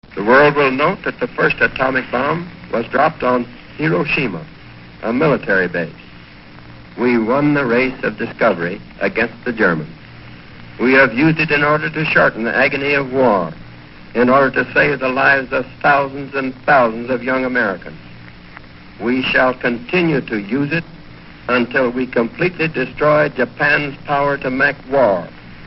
Tags: History Presidents Of the U. S. President Harry S. Truman Speeches